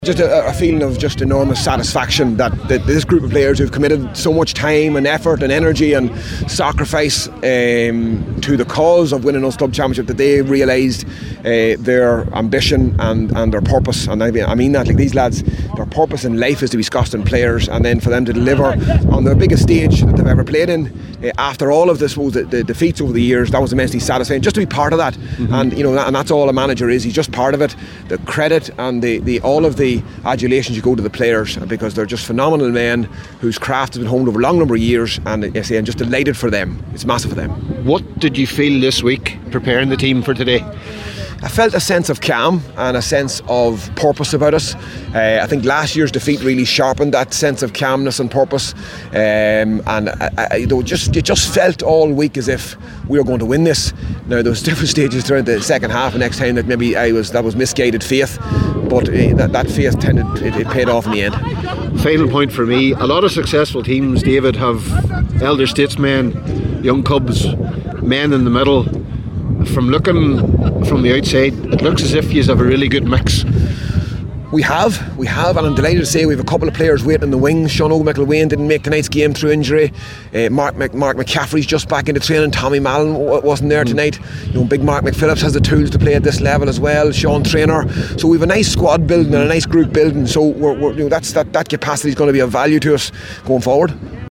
Post-match reaction